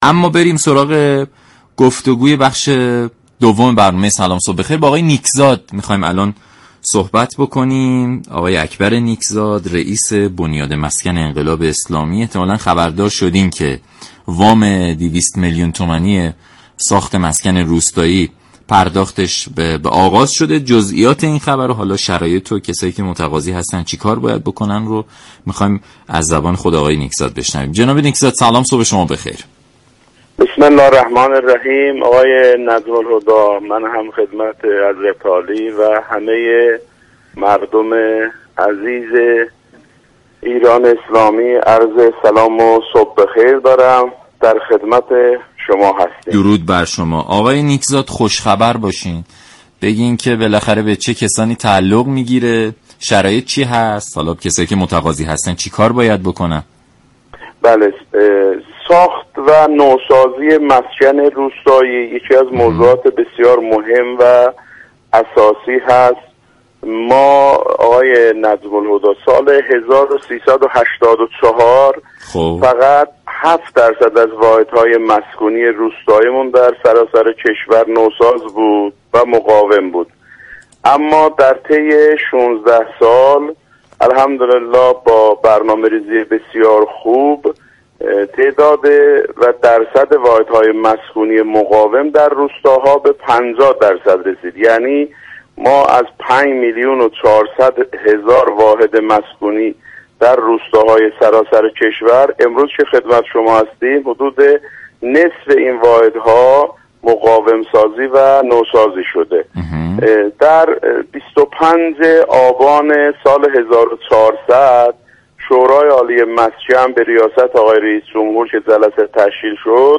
به گزارش شبكه رادیویی ایران، اكبر نیكزاد رییس بنیاد مسكن انقلاب اسلامی در برنامه «سلام صبح بخیر» درباره جزئیات جزئیات پرداخت وام 200 میلیون تومانی مسكن روستایی گفت: با دستور رئیس جمهور و همراهی سازمان برنامه و بودجه پرداخت تسهیلات 200 میلیون تومانی مسكن روستایی با سود 5 درصد از شنبه هشتم مرداد آغاز شده است.